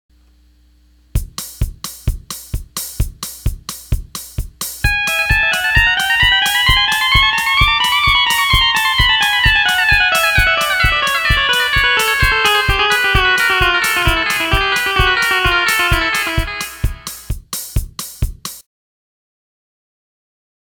Fast Echo Doubling Riff : The Steel Guitar Forum
Second solo
Fast Echo Blocked Tab 2.wma